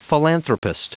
Prononciation du mot philanthropist en anglais (fichier audio)